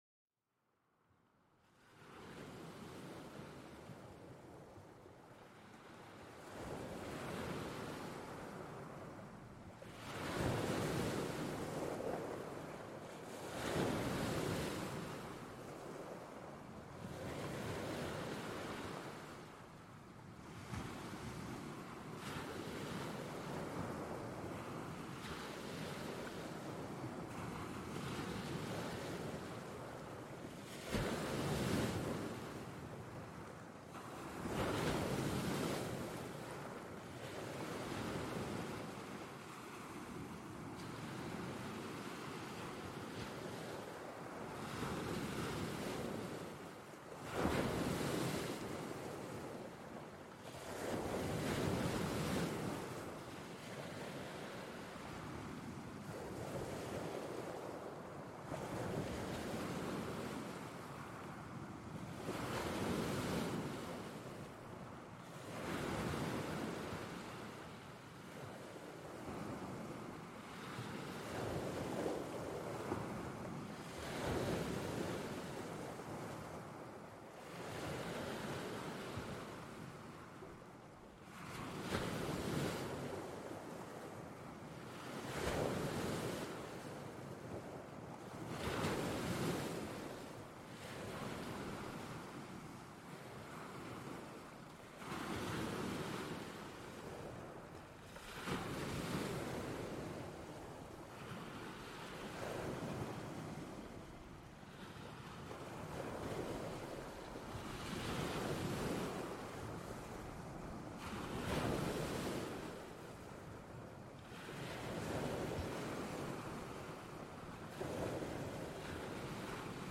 Plongez dans le son relaxant des vagues de l'océan se brisant sur les rochers. Ce murmure naturel vous emmènera dans un état de relaxation profonde.